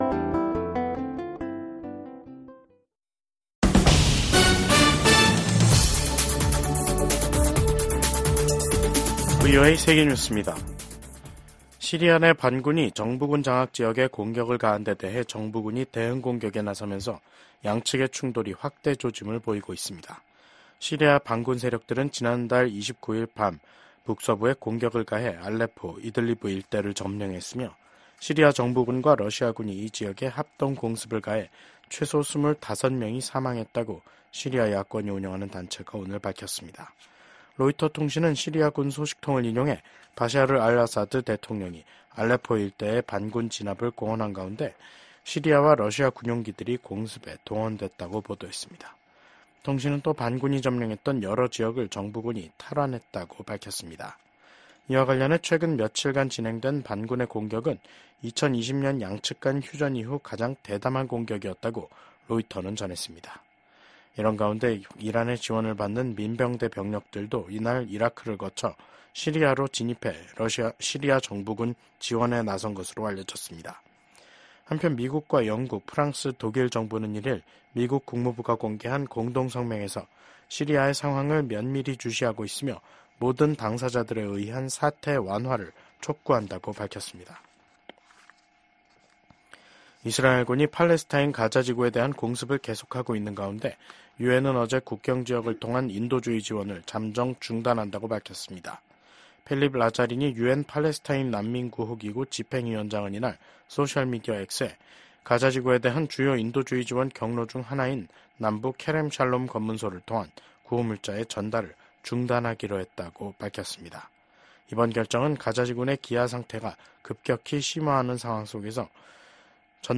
VOA 한국어 간판 뉴스 프로그램 '뉴스 투데이', 2024년 12월 2일 2부 방송입니다. 김정은 북한 국무위원장이 북한을 방문한 안드레이 벨로우소프 러시아 국방장관을 만나 우크라이나 전쟁과 관련해 러시아에 대한 지지 입장을 거듭 분명히 했습니다. 미국 국무부는 러시아 국방장관의 북한 공식 방문과 관련해 북러 협력 심화에 대한 우려 입장을 재확인했습니다.